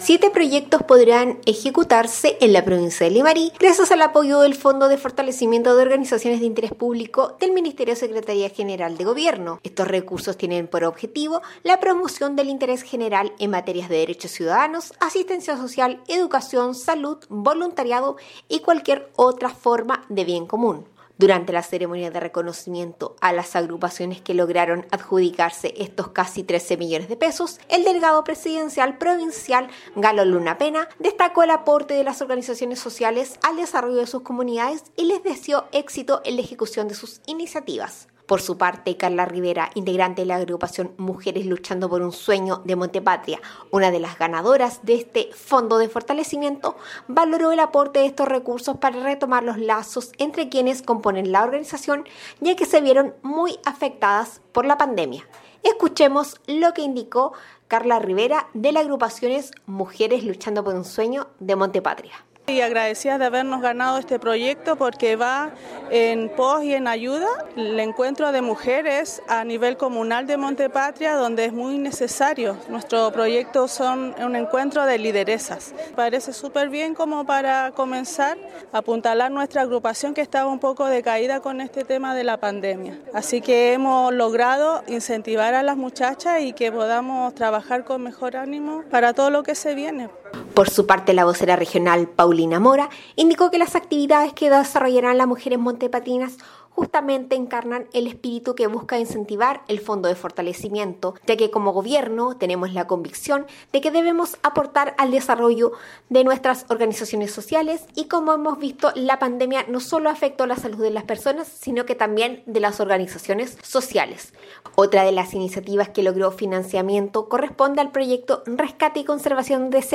DESPACHO-FFOIP-LIMARI.mp3